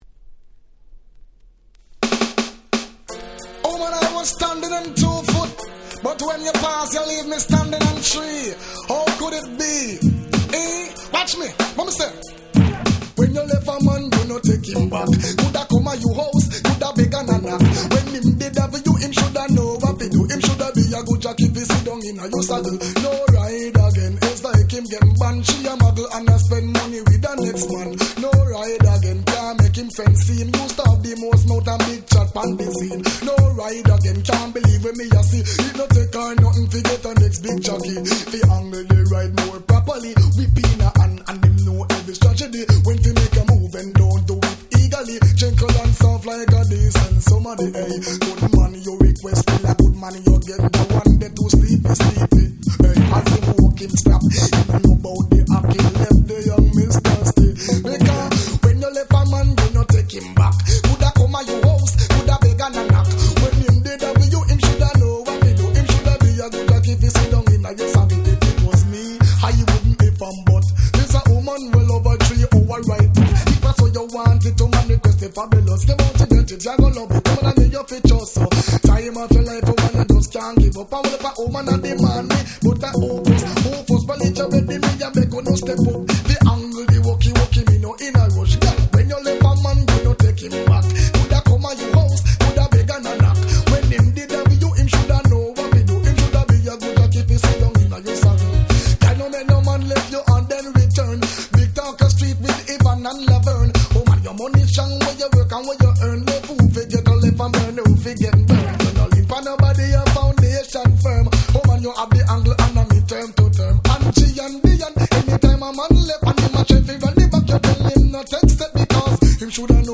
94' Super Dancehall Reggae Classic !!